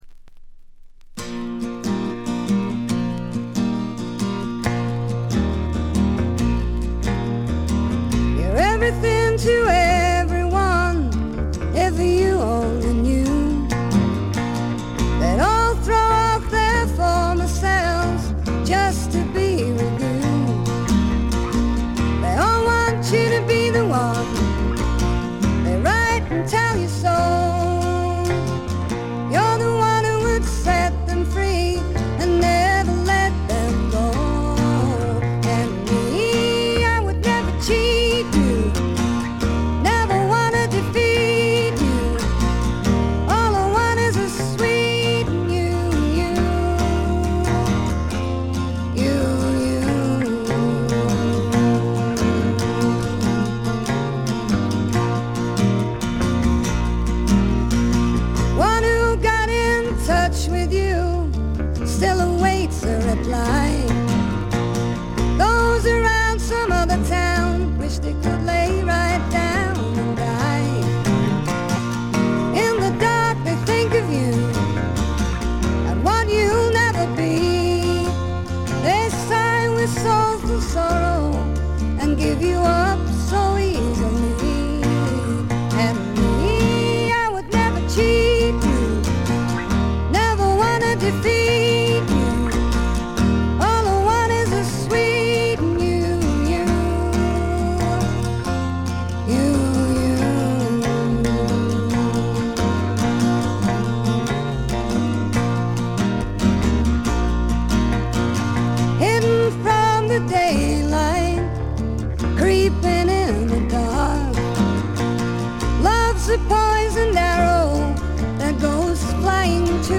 自身のギター弾き語りにベースやセカンド・ギター、マンドリン、サックスが付くといったシンプルでアコースティックな編成です。
またドラムレスながらベースが付いてほとんどフォーク・ロックです。
試聴曲は現品からの取り込み音源です。
Vocals, Guitar